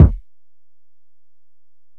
Kick (35).wav